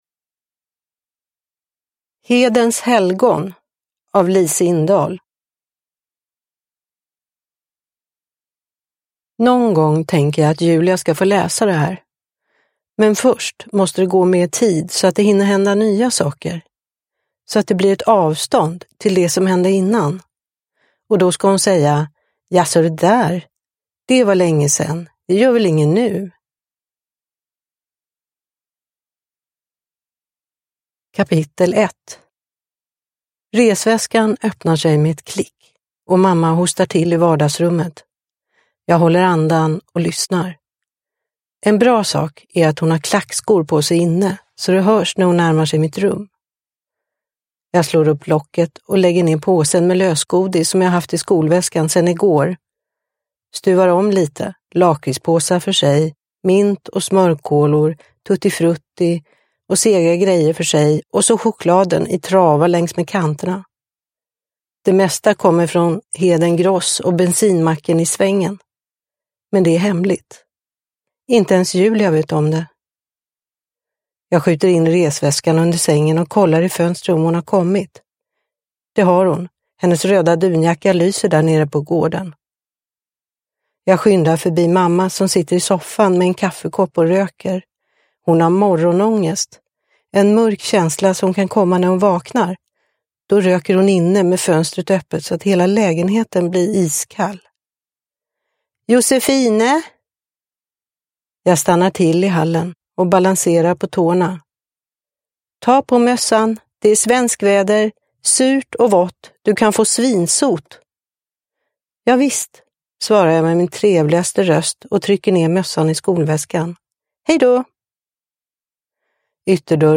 Hedens helgon – Ljudbok – Laddas ner